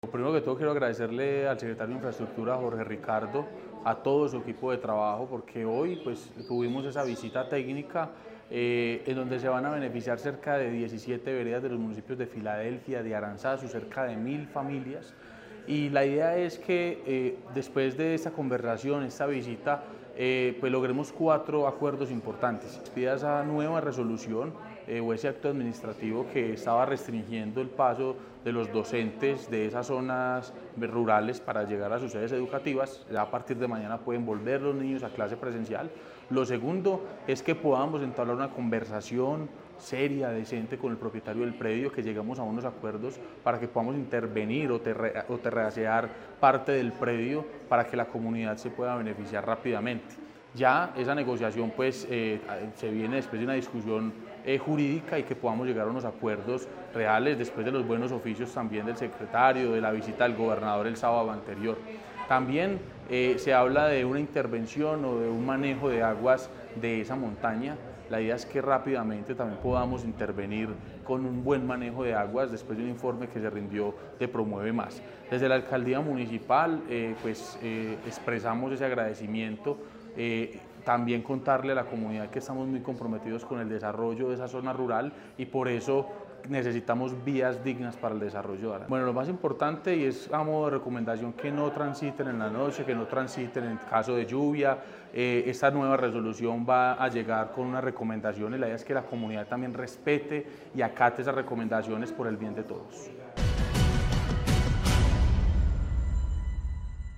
Sebastián Merchán, alcalde de Aranzazu.